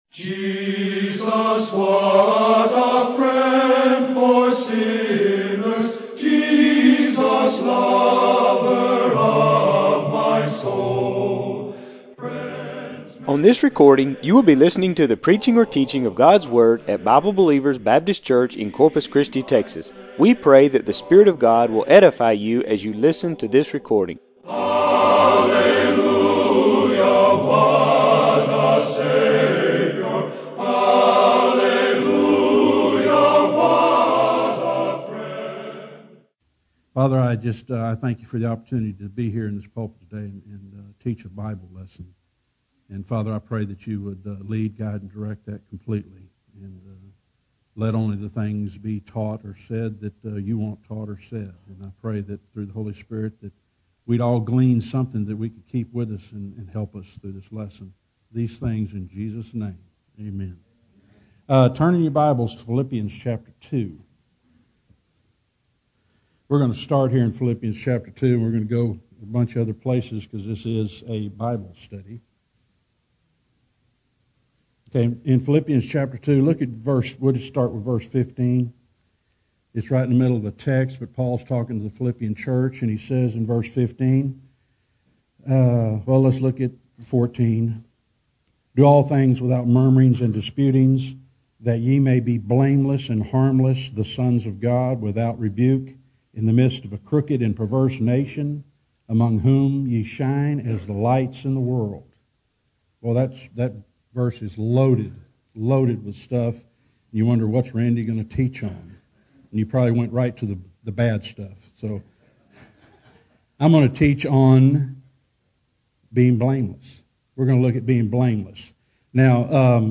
This is a Sunday school lesson on how you can be blameless according to Phil 2:14-15 – Paul was blameless in Phil 3:6 and Zacharias and Elisabeth were blameless in Luke 1:6.